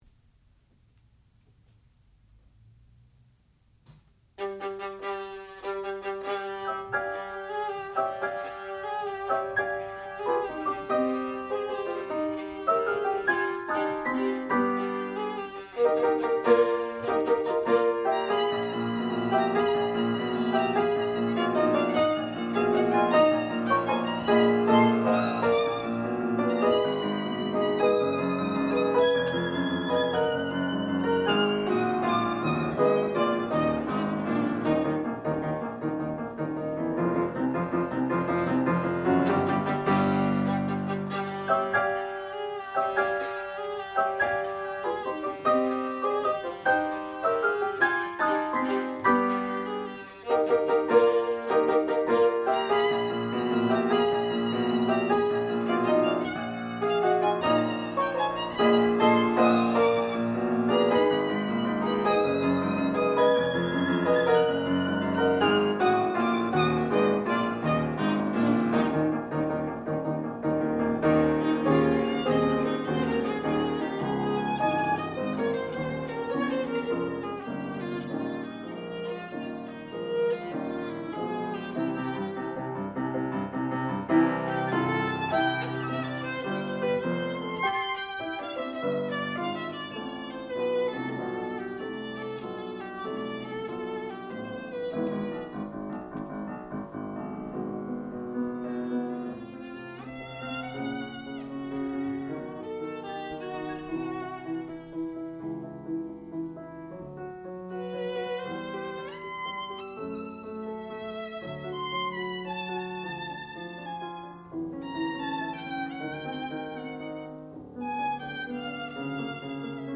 sound recording-musical
classical music
Advanced Recital
violin